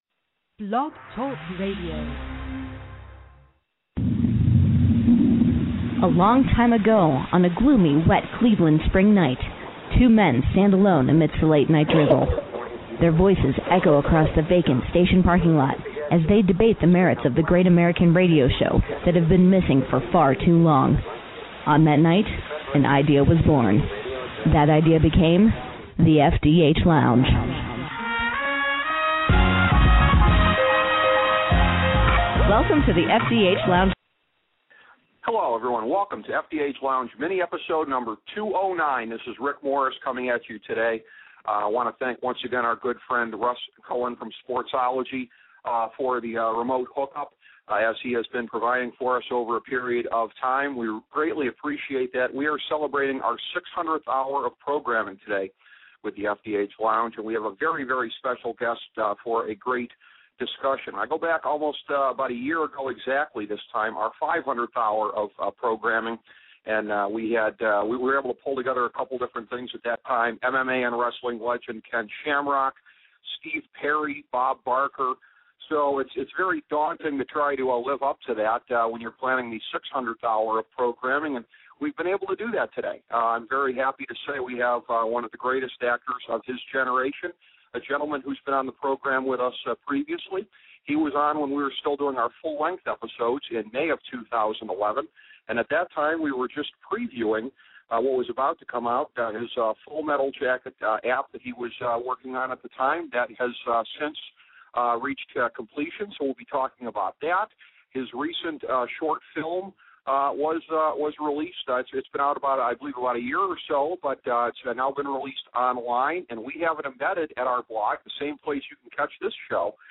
A conversation with actor Matthew Modine